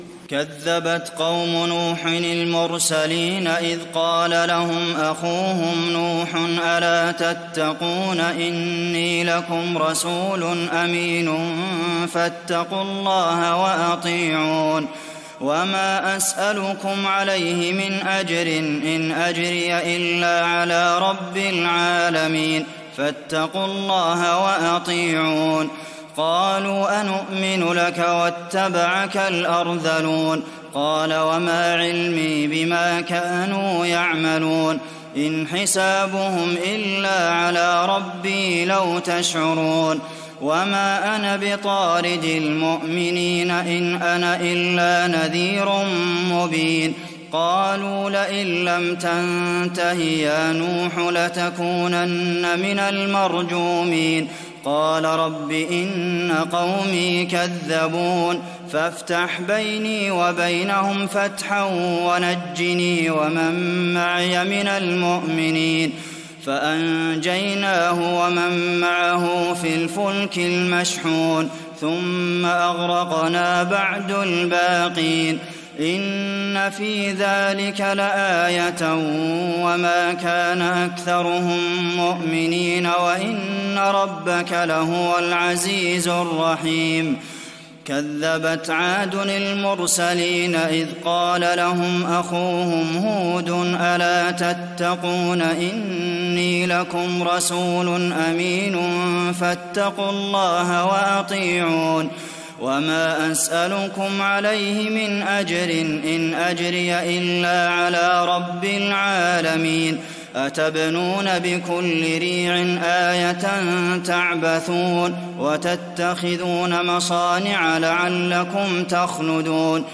تراويح الليلة الثامنة عشر رمضان 1419هـ من سورتي الشعراء (105-227) والنمل (1-53) Taraweeh 18th night Ramadan 1419H from Surah Ash-Shu'araa and An-Naml > تراويح الحرم النبوي عام 1419 🕌 > التراويح - تلاوات الحرمين